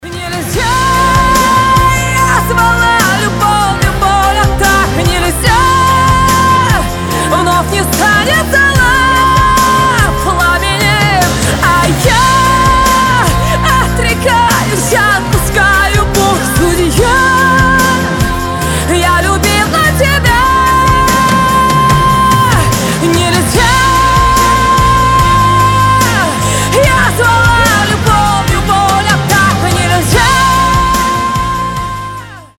• Качество: 320, Stereo
поп
громкие
грустные
красивый женский вокал